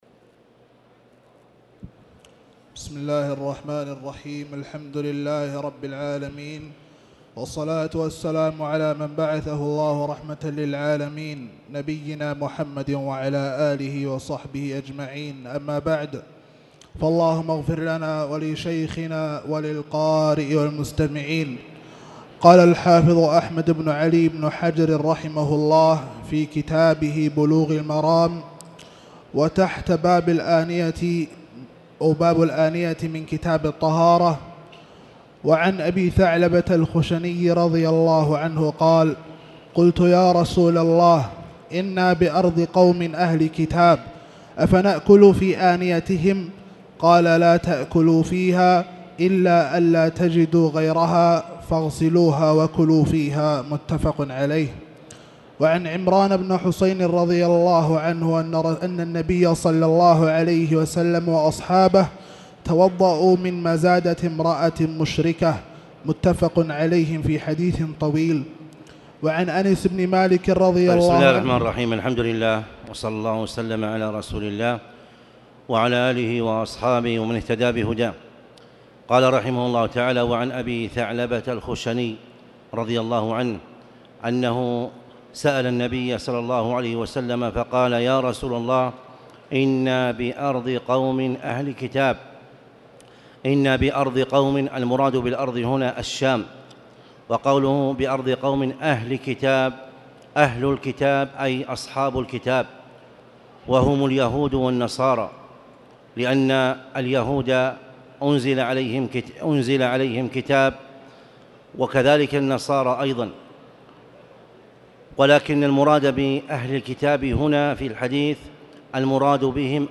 تاريخ النشر ٢٣ ربيع الأول ١٤٣٨ هـ المكان: المسجد الحرام الشيخ